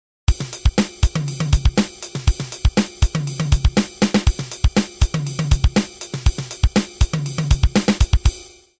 This is a deceptively difficult pattern, at least for me. I discovered that catching the bounced kick on the +a of 3 is difficult when I’m playing offbeats on the high-hat with my other foot, because the two feet (apparently) have to lift at different times even though they play simultaneously on the + of 3.